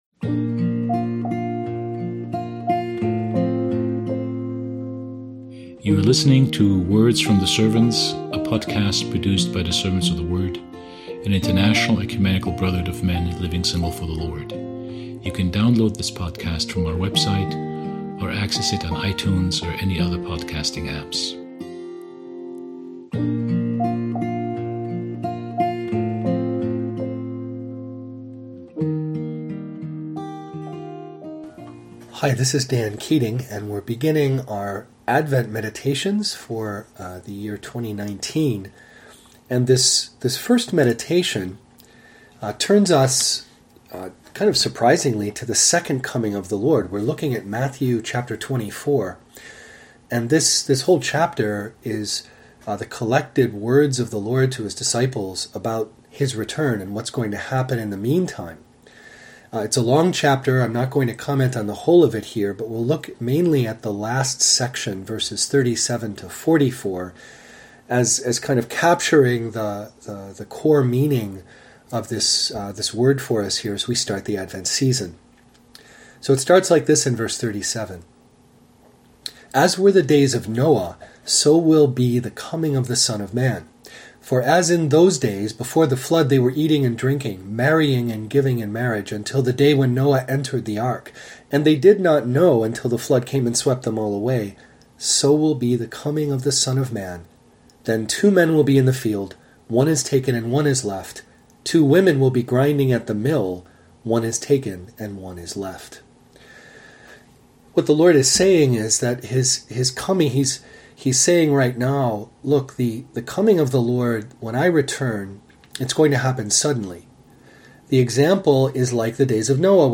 provides commentary on Matt 24:1-44 to start off our Advent meditations.